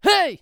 CK发力07.wav
CK发力07.wav 0:00.00 0:00.43 CK发力07.wav WAV · 37 KB · 單聲道 (1ch) 下载文件 本站所有音效均采用 CC0 授权 ，可免费用于商业与个人项目，无需署名。
人声采集素材/男2刺客型/CK发力07.wav